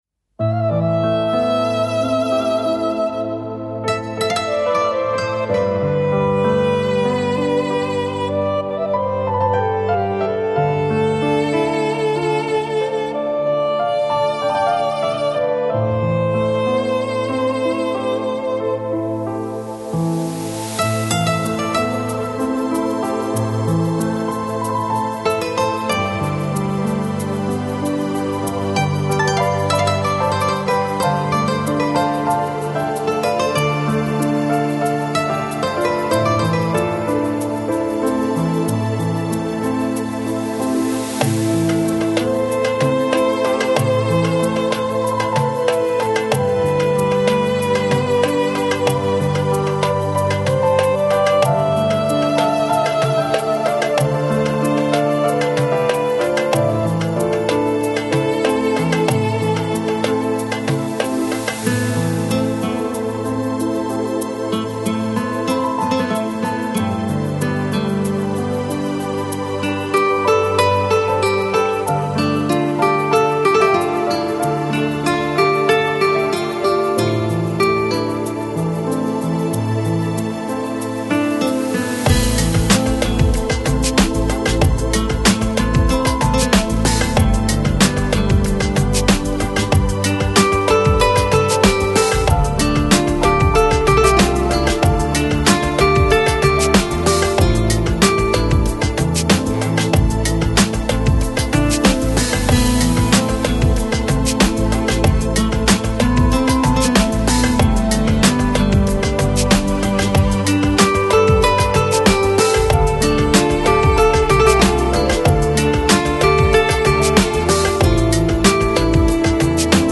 Жанр: Chill Out, Downtempo, Organic House, Ethnic, World